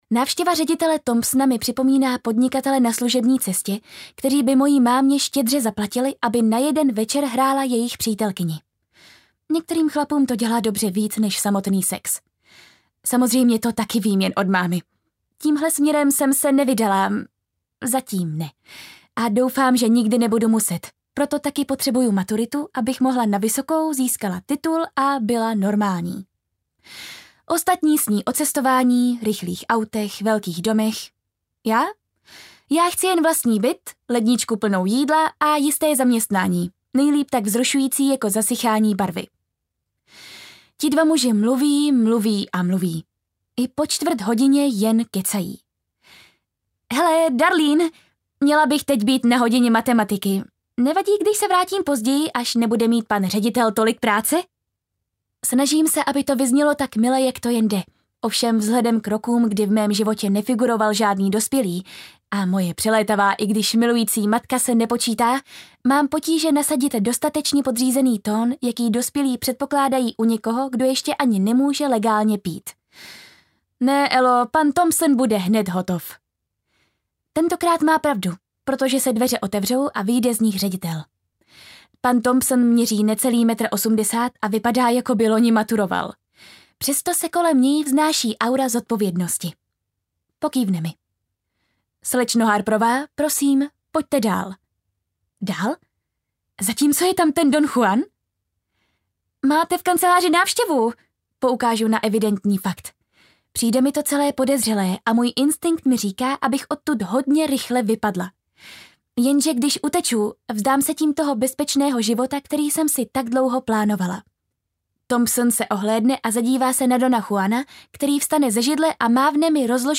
Papírová princezna audiokniha
Ukázka z knihy